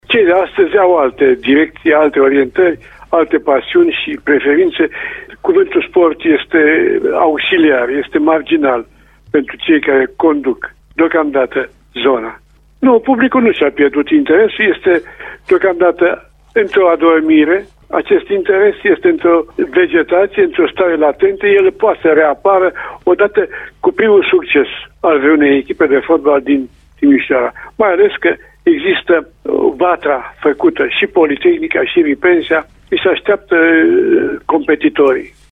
Într-un interviu la Radio Timișoara, Ion V Ionescu a vorbit și despre lipsa de interes pentru sport a actualelor oficialități locale: